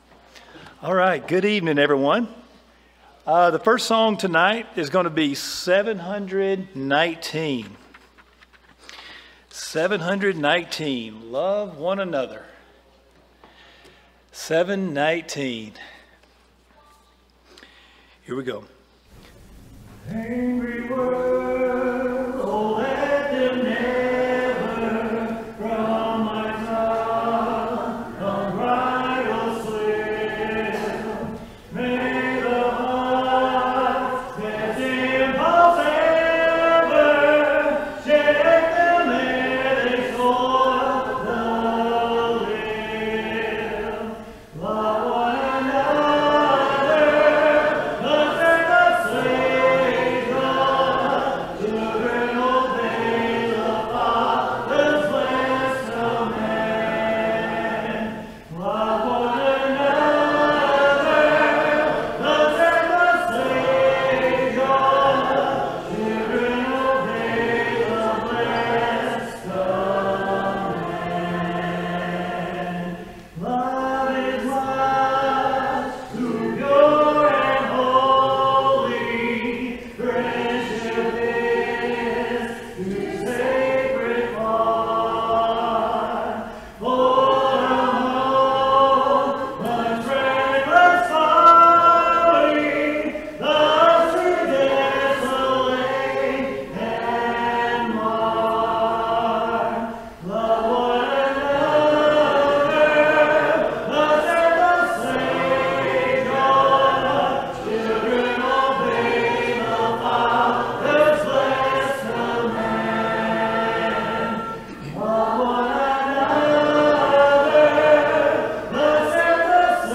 Nahum, 1:7, English Standard Version Series: Sunday PM Service